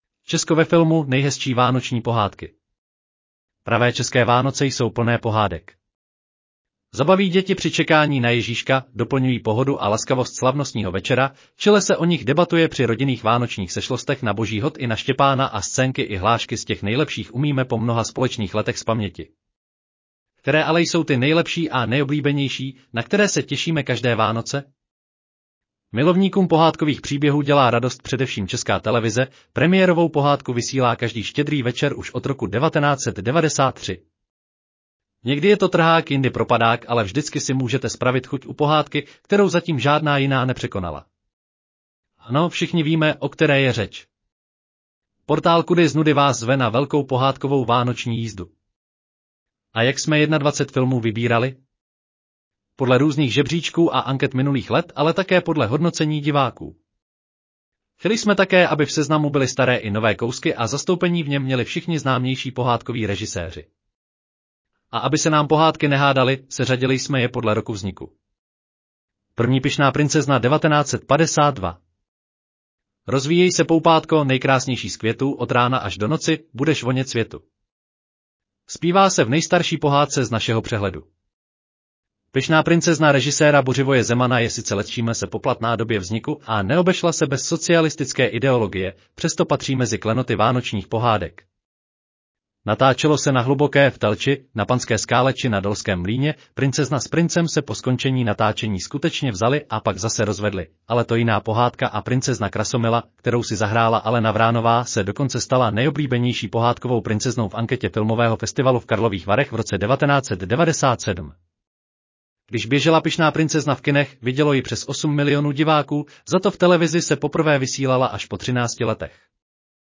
Audio verze článku Česko ve filmu: nejhezčí vánoční pohádky